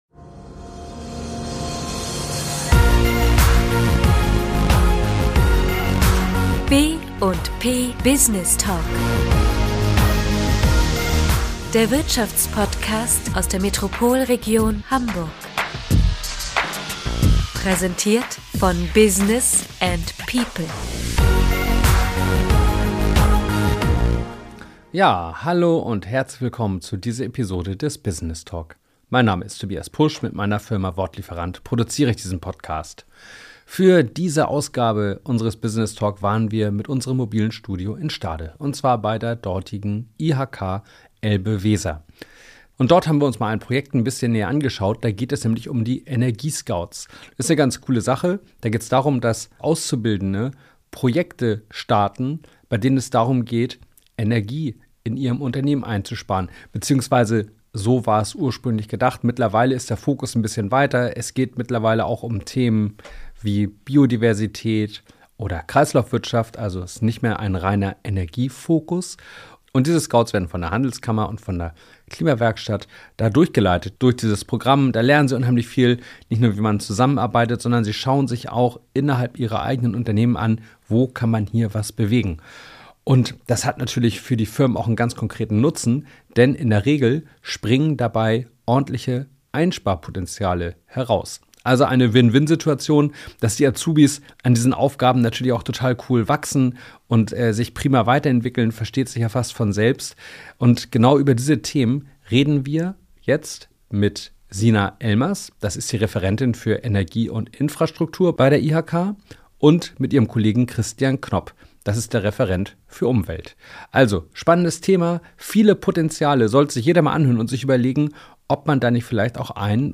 Beschreibung vor 1 Jahr Für diese Ausgabe des BusinessTalk waren wir mit dem mobilen Studio in Stade, und zwar bei der dortigen IHK Elbe-Weser.